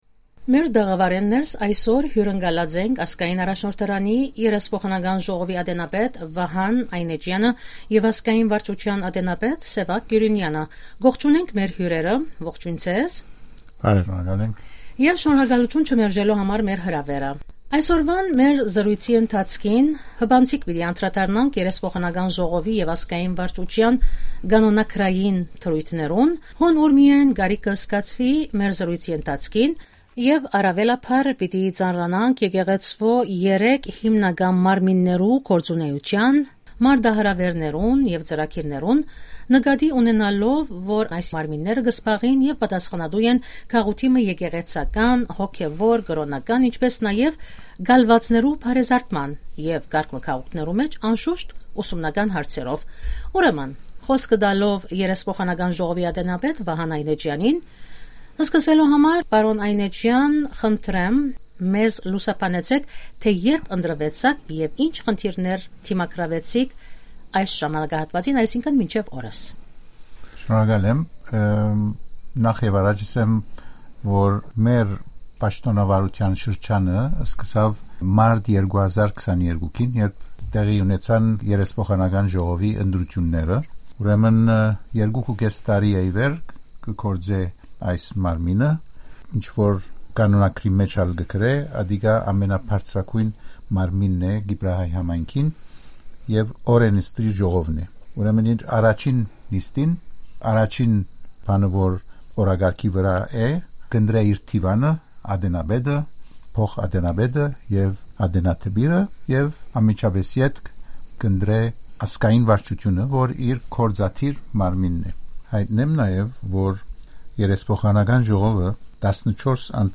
Συνέντευξη - Interview